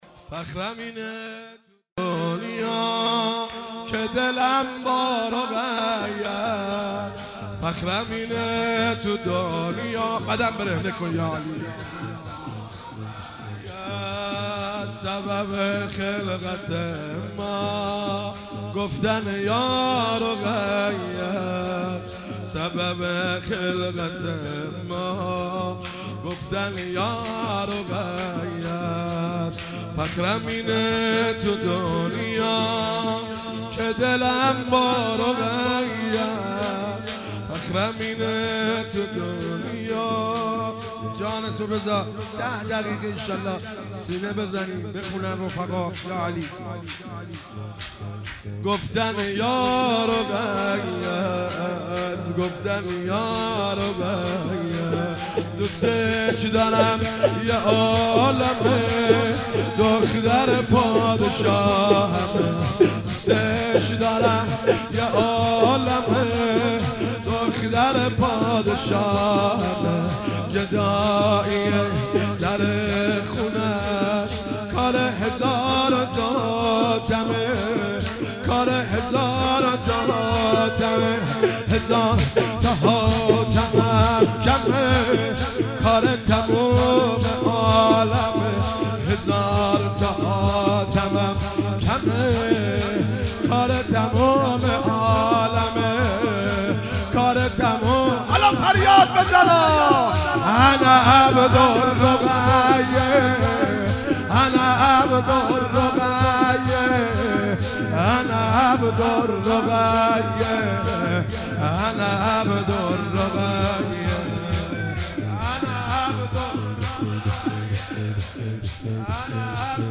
ولادت حضرت رقیه سلام الله علیها